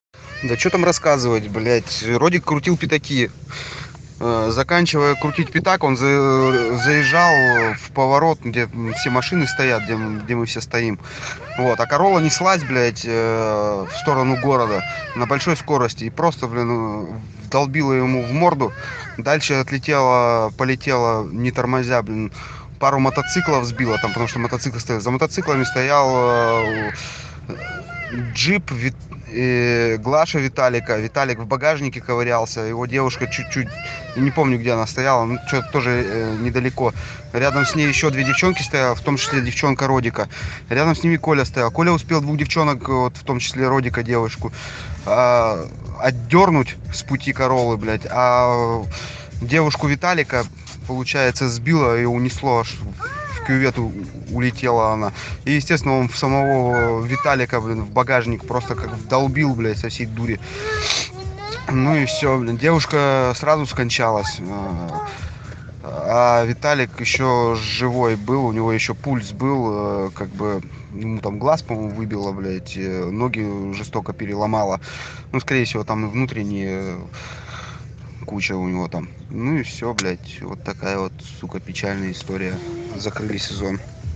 Как это было со слов очевидца аварии. В записи есть нецензурная лексика.